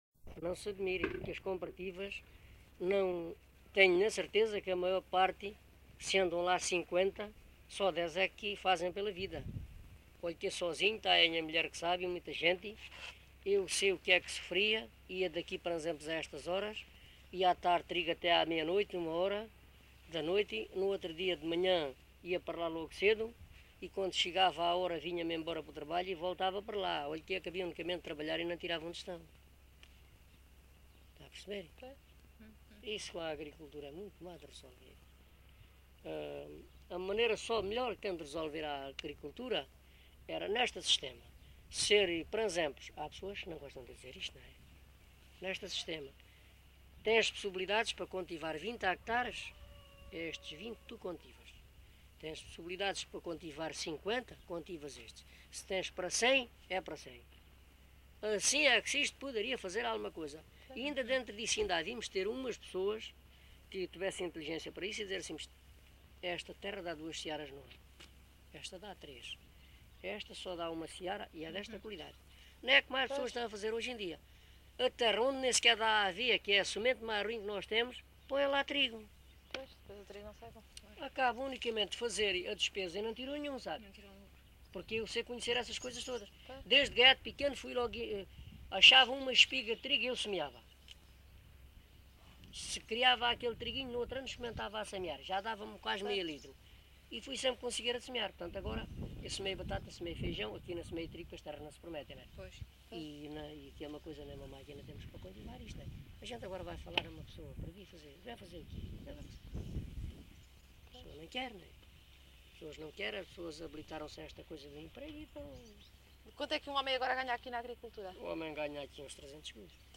LocalidadeMelides (Grândola, Setúbal)